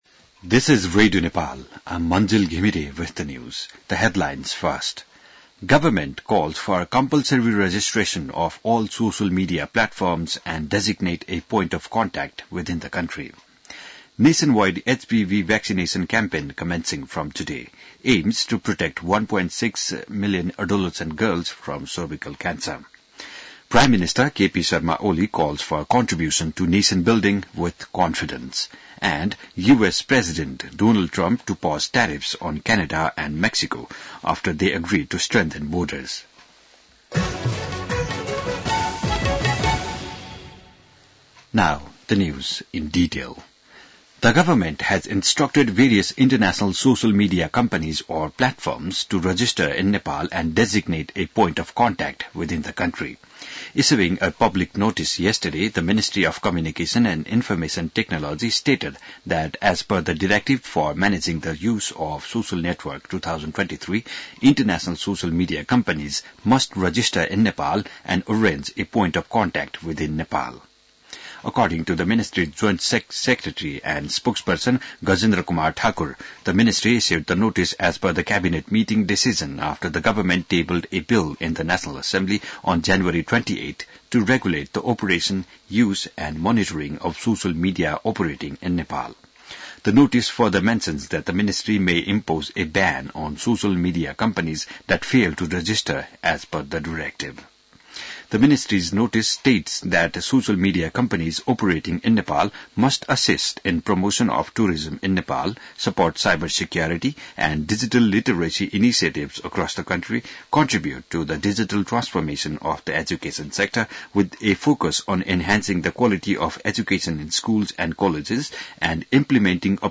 बिहान ८ बजेको अङ्ग्रेजी समाचार : २३ माघ , २०८१